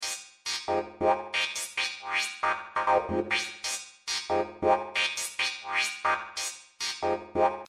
胸部狂欢合成器循环
描述：胸部狂欢合成器循环
Tag: 126 bpm Rave Loops Synth Loops 1.29 MB wav Key : Unknown